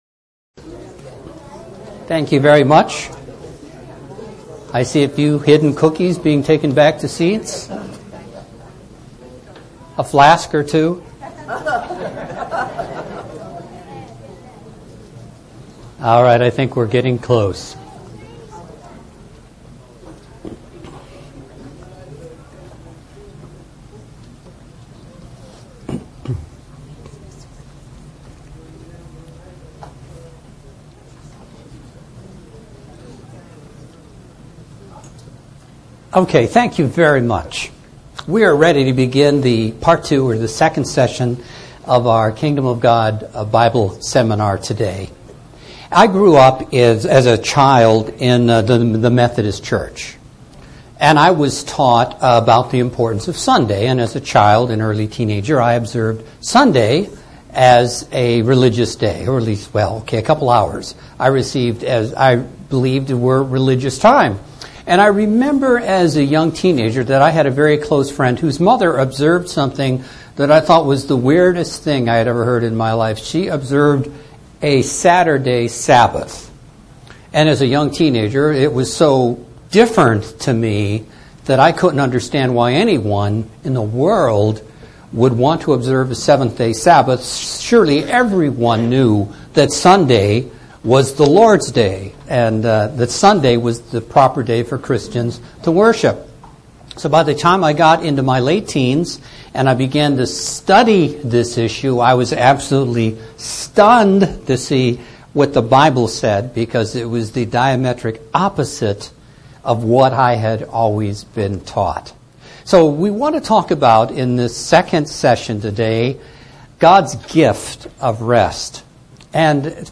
God's Holy Sabbath Day vividly portrays a vital step in God's plan for all Mankind. Let's look at what our future holds through this Kingdom of God seminar.